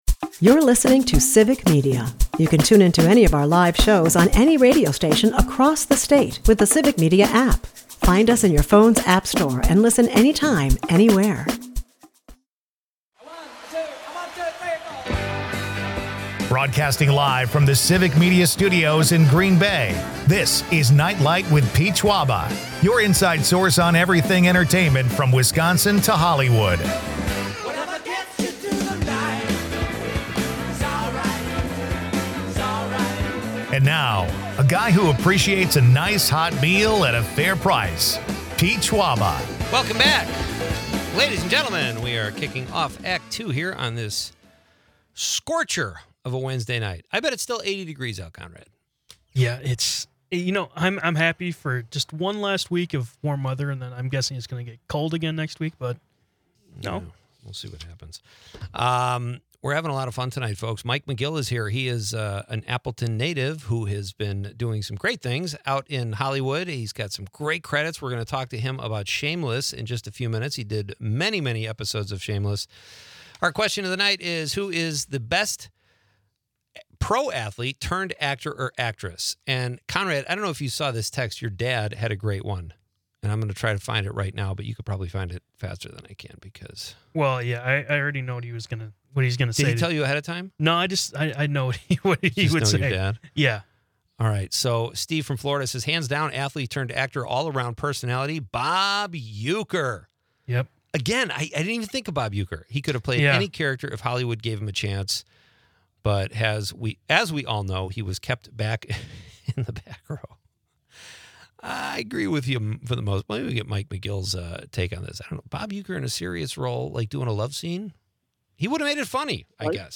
The hosts and guests humorously debate the best athletes-turned-actors, with nods to David Bautista and Jason Statham, and discuss bad stadium behavior, including a viral Phillies game incident.